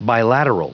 Prononciation du mot : bilateral